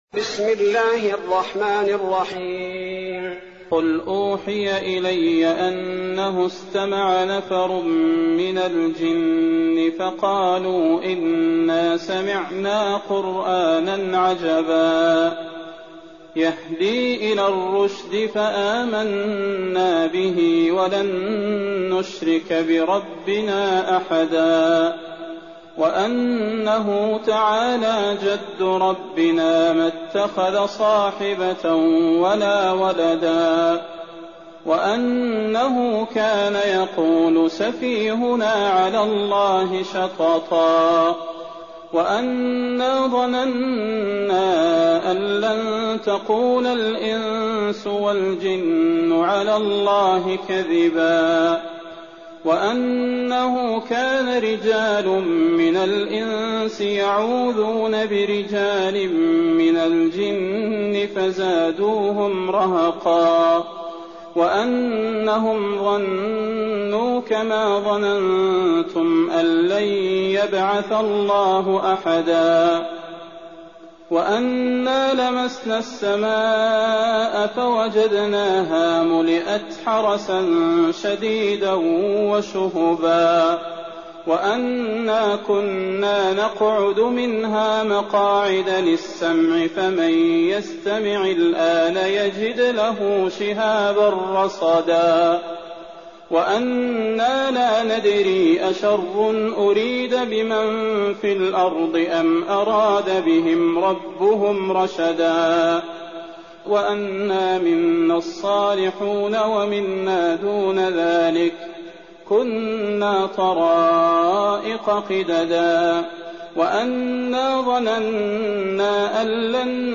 تراويح ليلة 28 رمضان 1419هـ من سورة الجن الى المرسلات Taraweeh 28th night Ramadan 1419H from Surah Al-Jinn to Al-Mursalaat > تراويح الحرم النبوي عام 1419 🕌 > التراويح - تلاوات الحرمين